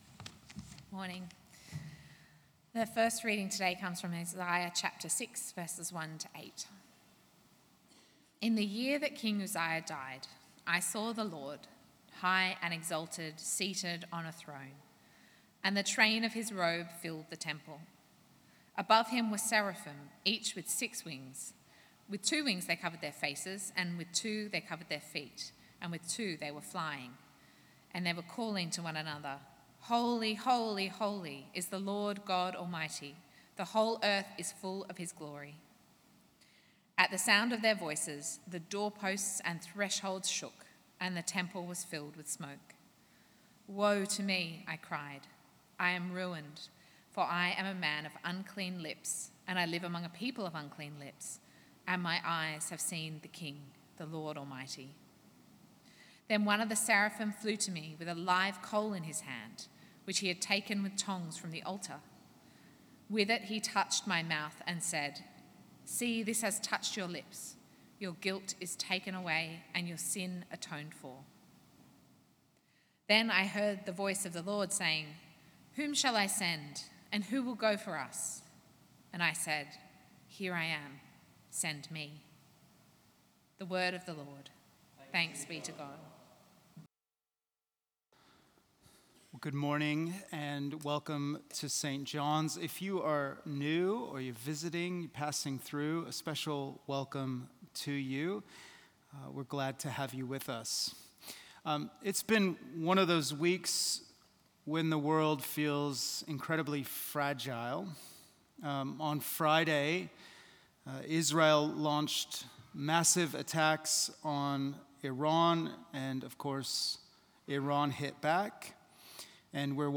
A sermon on Isaiah 6:1-8
delivered at St John's Darlinghurst in Sydney, Australia on 15 June 2025.